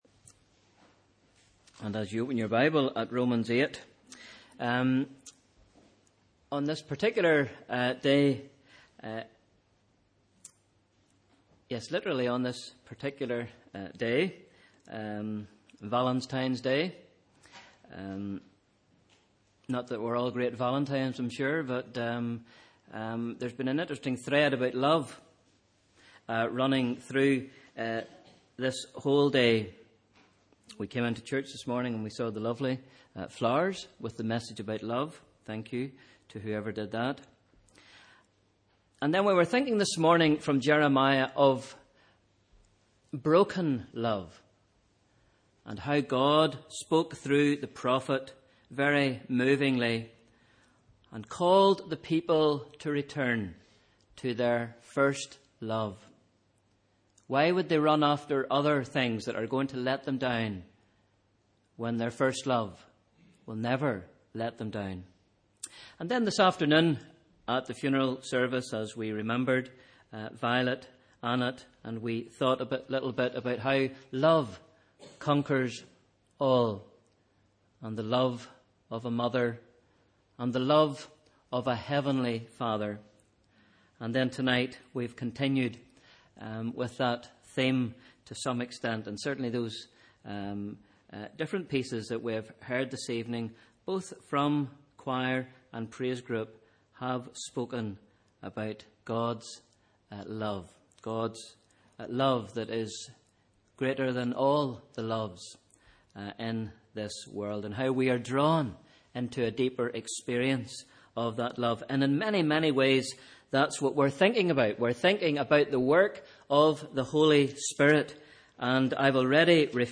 Sunday 14th February 2016 – Evening Service